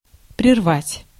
Ääntäminen
IPA : /dɪskənˈtɪnju/